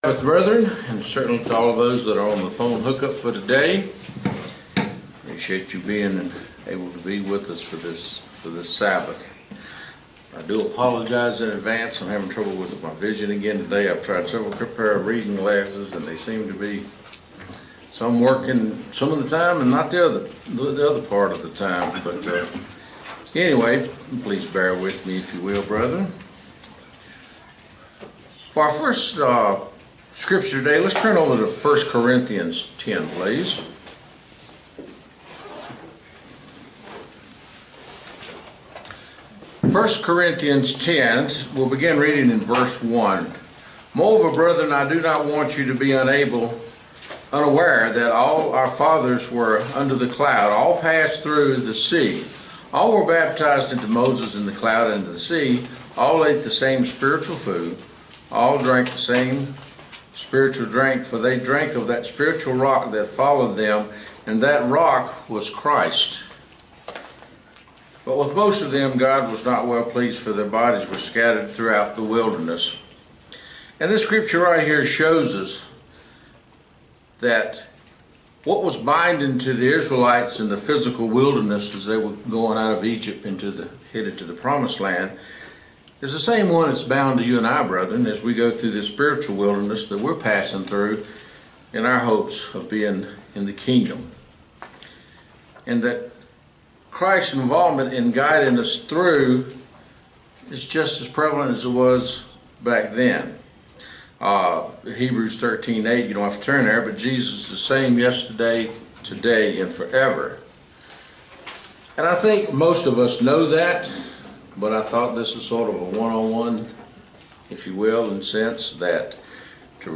Given in Roanoke, VA